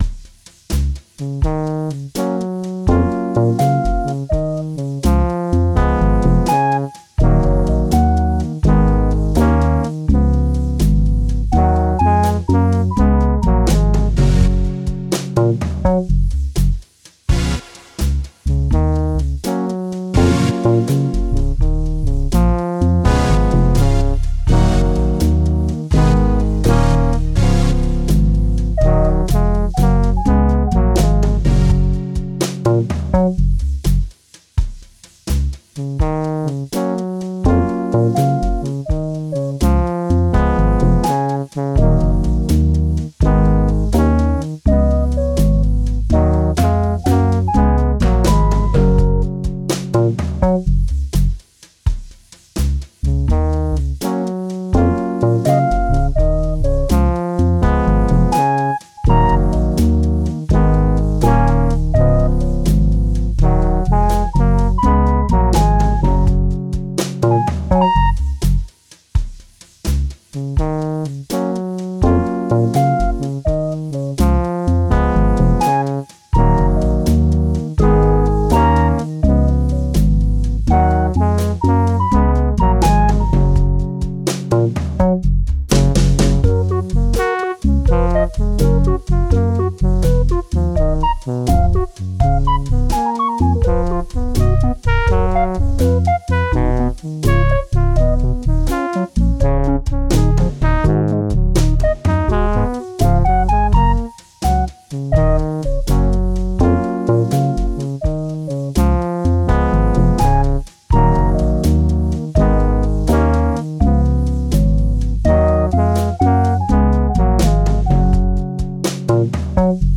Nordic Wavedorf” created in 2009 using five instances of U-he Zebra 2, an instance of Drum Core 3, fed through IK Multimedia’s TrackS 1, I got the urge to post it so anyone could have a listen.